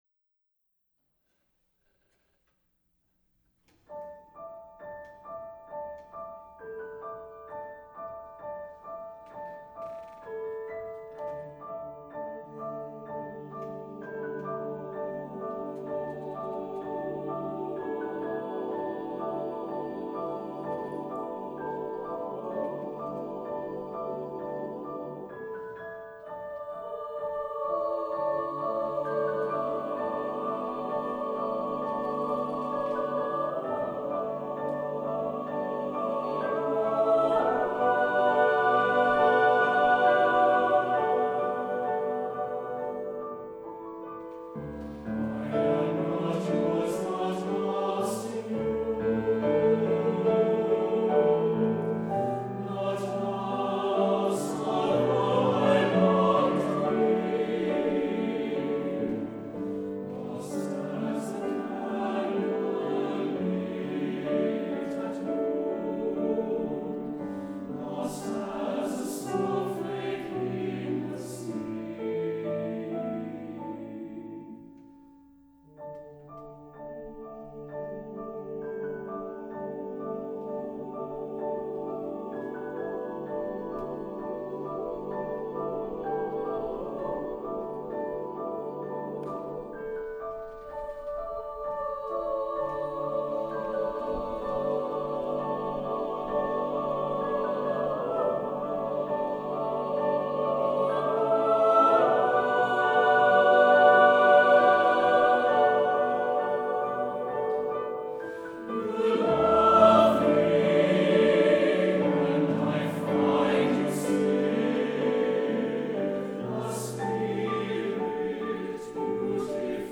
Accompaniment:      With Piano
Music Category:      Choral
Here is an impressive yet romantically intimate piece